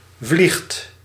Ääntäminen
Ääntäminen Tuntematon aksentti: IPA: /vlixt/ Haettu sana löytyi näillä lähdekielillä: hollanti Käännöksiä ei löytynyt valitulle kohdekielelle.